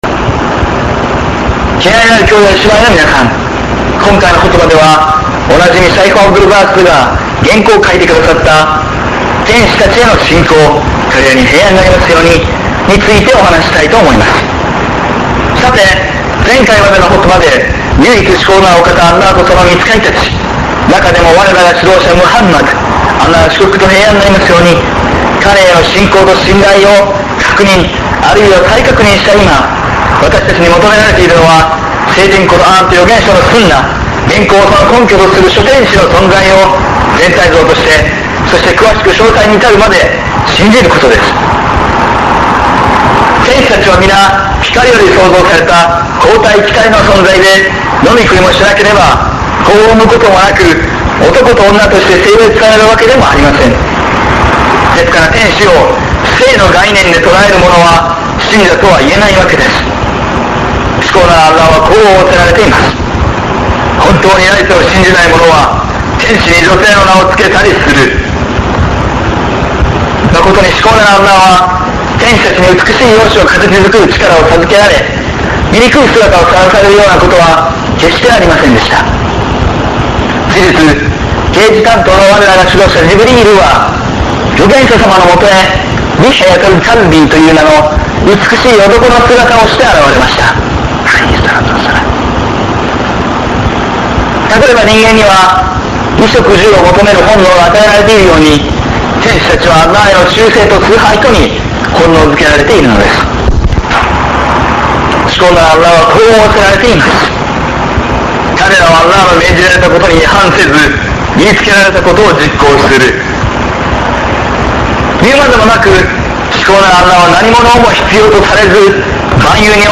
講義・説教